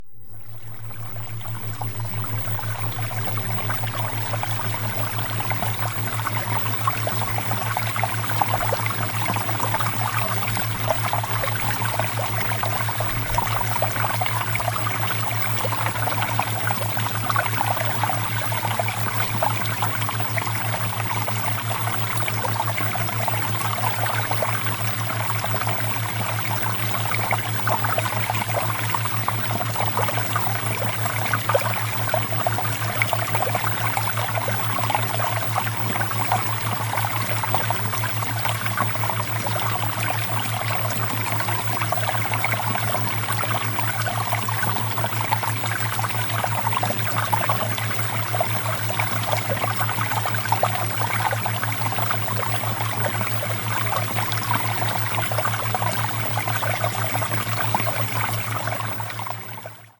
Theta Waves tracks include added brainwave entrainment. These audios use special tones to influence your brainwave patterns, helping to put you into a deep, relaxing state of mind, open and receptive to subliminal messages.
MAGNETIC-WEALTH-ATTRACTION-Water-Theta-Waves-Sample.mp3